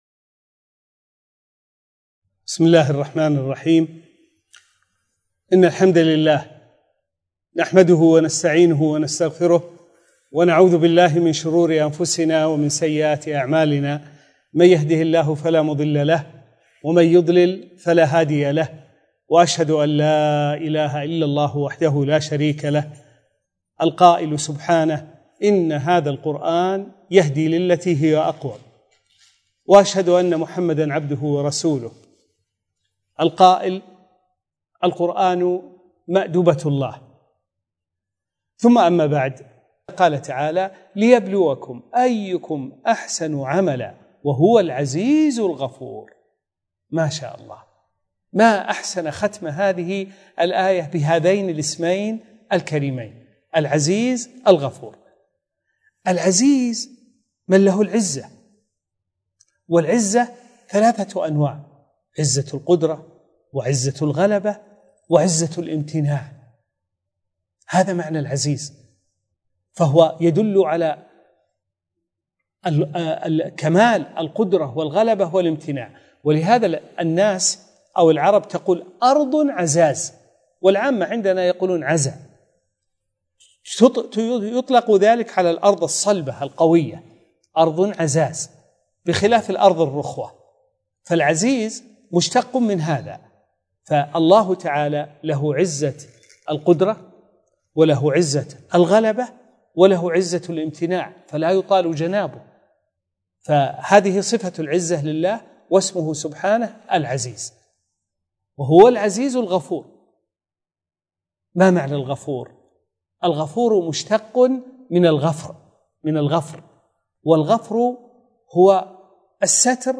الدرس الثاني : سورة تبارك: (من قوله تعالى: (العزيز الغفور)، إلى قوله تعالى: (فَسُحْقًا لِأَصْحَابِ السَّعِيرِ).